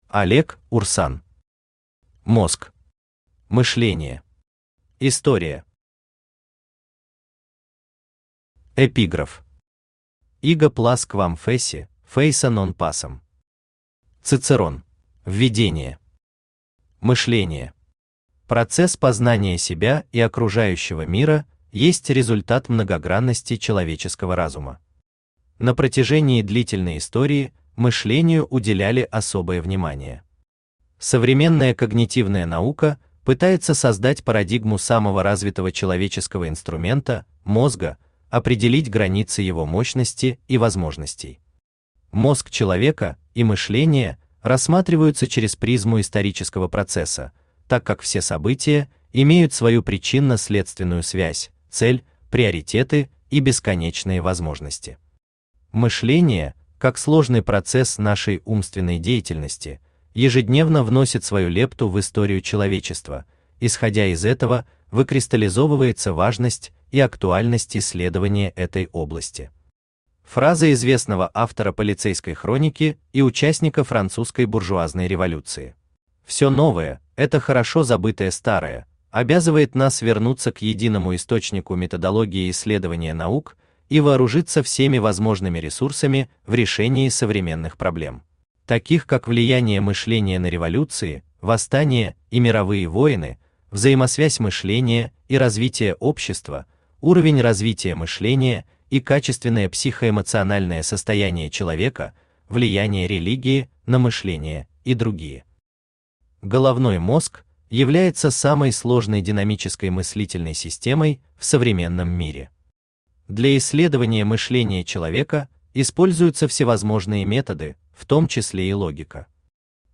Аудиокнига Мозг. Мышление. История | Библиотека аудиокниг
История Автор Олег Иванович Урсан Читает аудиокнигу Авточтец ЛитРес.